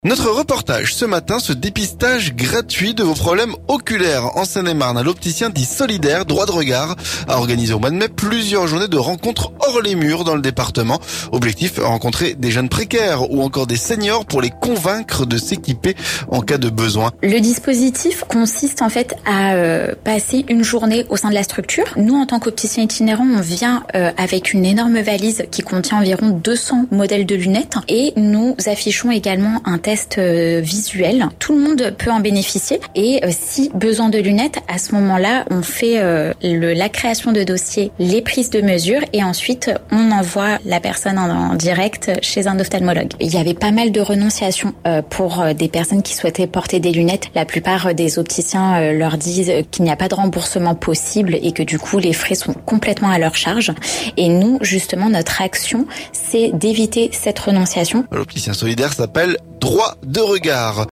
Notre reportage : dépistage gratuit de vos problèmes oculaires en Seine-et-Marne. L'opticien dit solidaire Droits de regard a organisé en mai plusieurs journées de rencontres hors les murs dans le département.